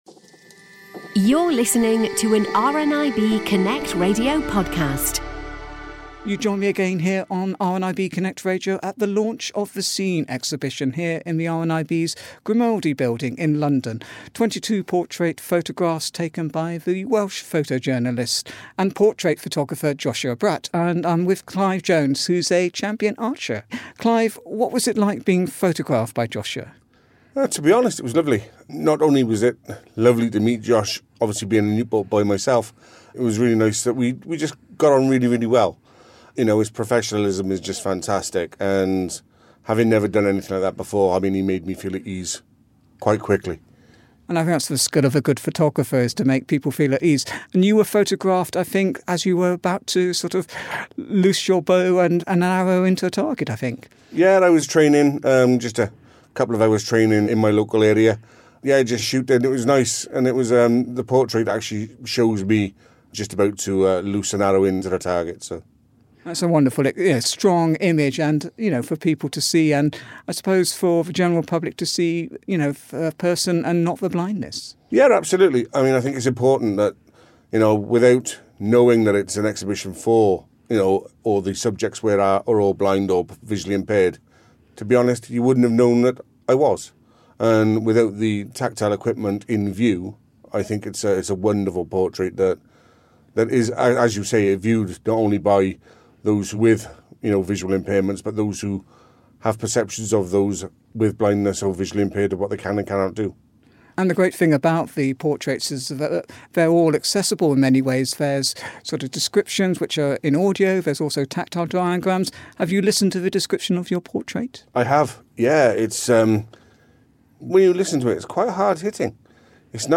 At Seen Exhibition 4 - Interview